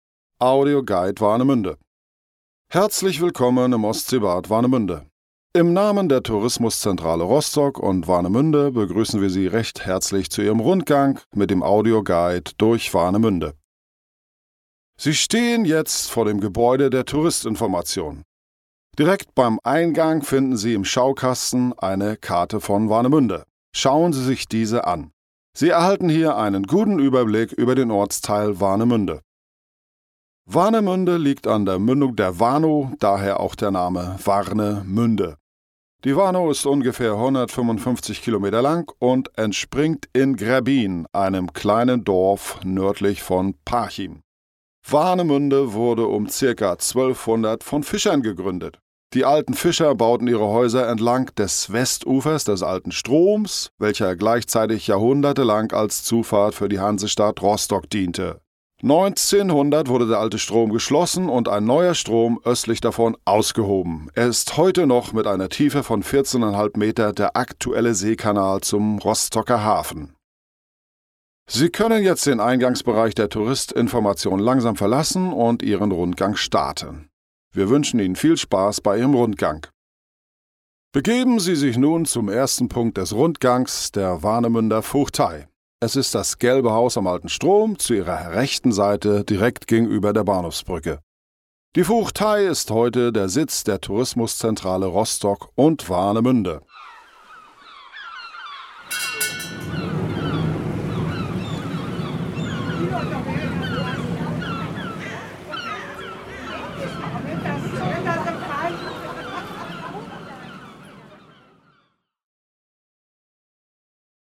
Audioguide
Aufnahmestudio: Tonstudio Rostock